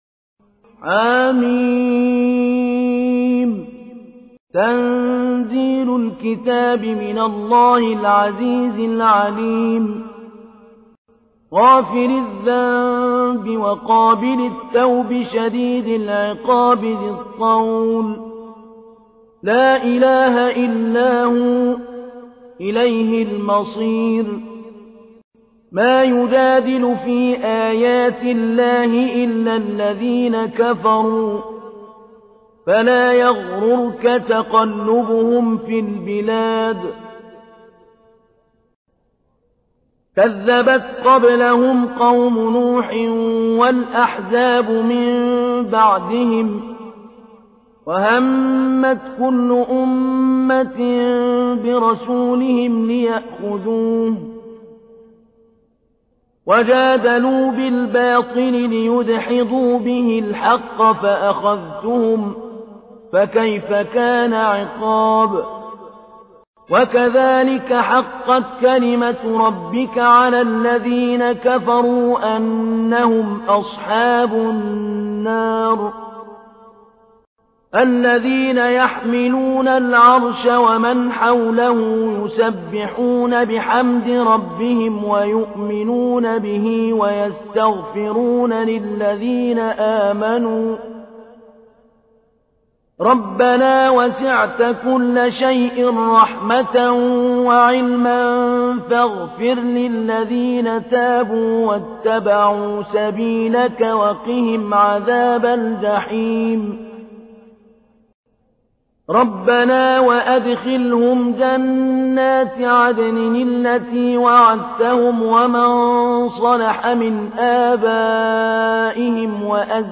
تحميل : 40. سورة غافر / القارئ محمود علي البنا / القرآن الكريم / موقع يا حسين